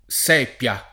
sepia [ S$ p L a ]